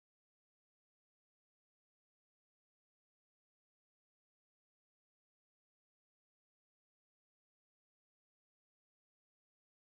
silence-ring.mp3